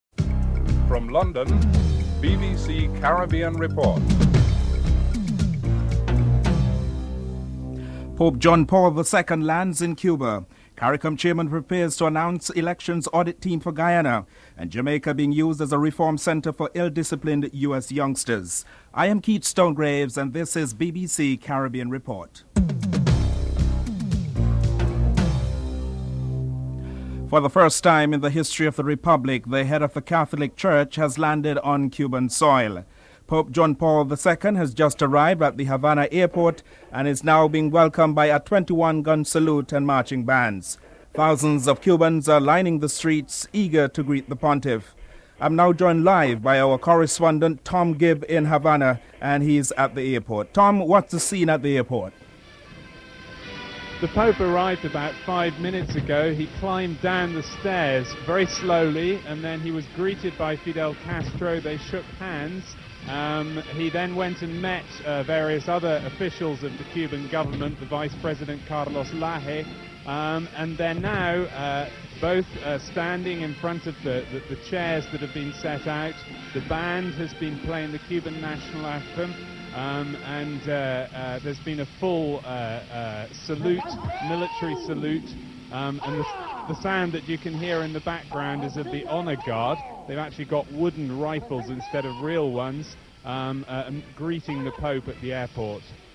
6. As the Caribbean lobbies to prevent nuclear wastes shipments through its waters, a Caribbean radiation physicist states that these shipments pose minimum risk (13:05-15:20)